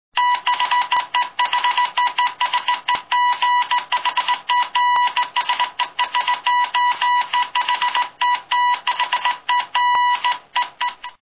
Morse Code ringtone download